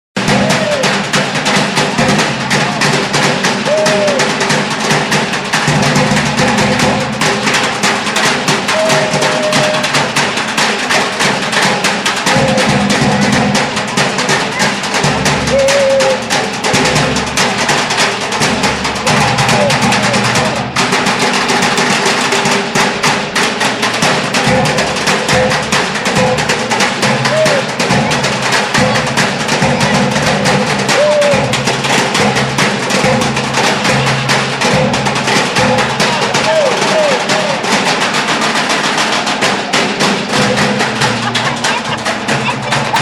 Glamba Samba played on bins